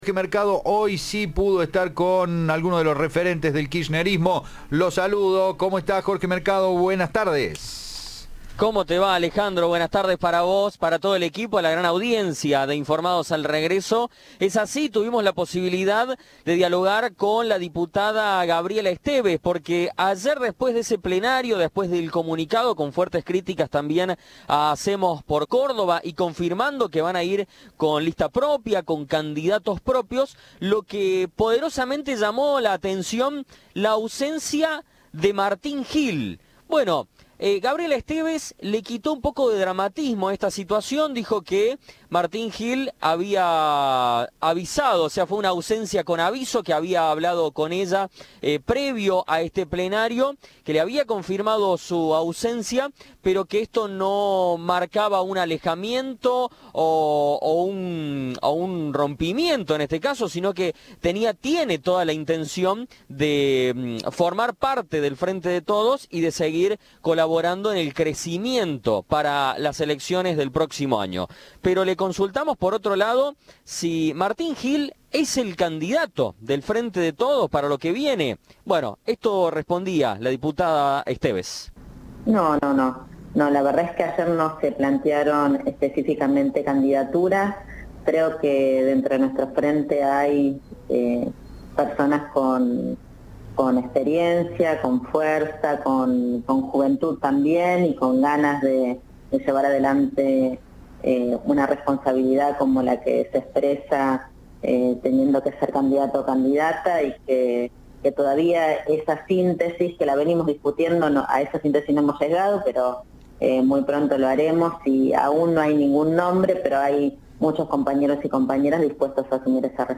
El Frente de Todos anunció que en Córdoba presentará su lista y candidatos propios en las elecciones de 2023. En ese contexto, Cadena 3 entrevistó  a sus referentes en la provincia.